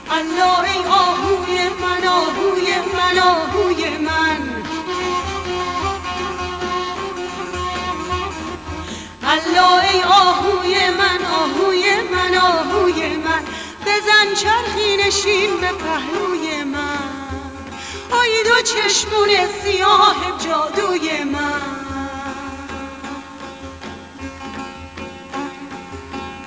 Folk Music of Khorasan
Vocals
Ney, Daf
Setar